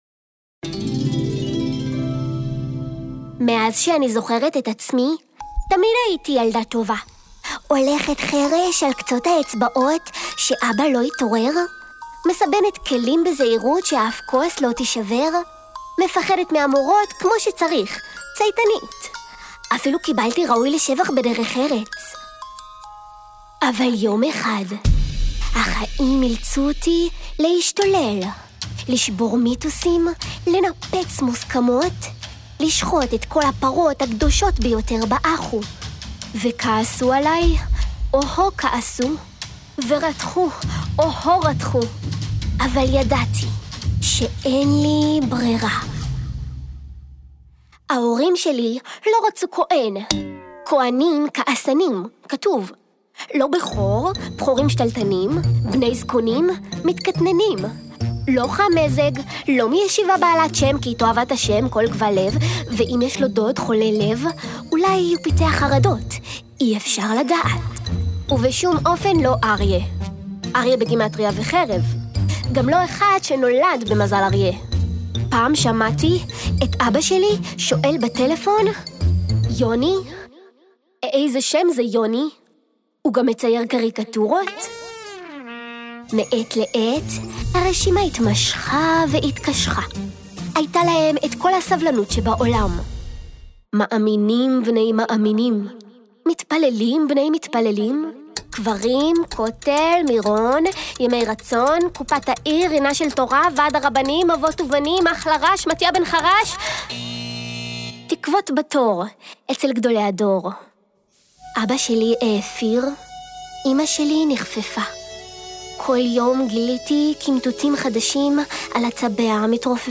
ל"ספוקן וורד" יש מנגינה מיוחדת שממש כיף להיכנס אליה; לכל מילה מגיע הניגון הייחודי שלה, שמשחיז ומשייף אותה עד שהיא כמעט ועומדת בפני עצמה… ובפרט בכתיבה מסוג כזה שמקפלת רבדים נוספים בין השורות.